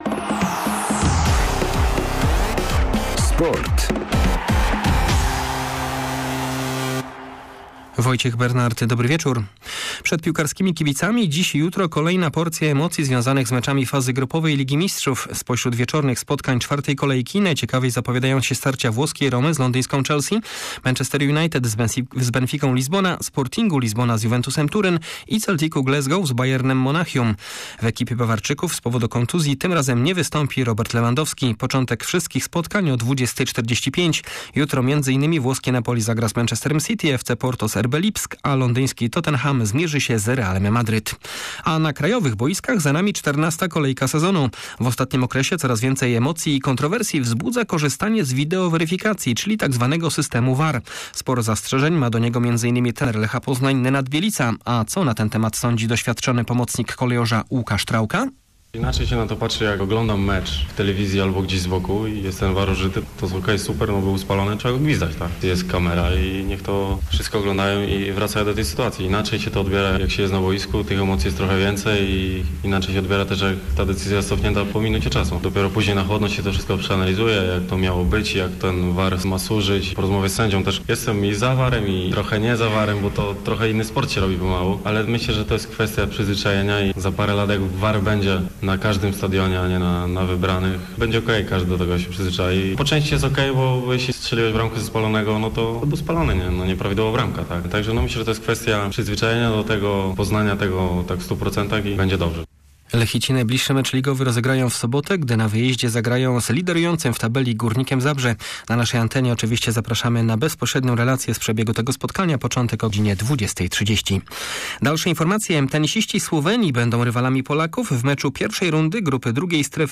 31.10 serwis sportowy godz. 19:05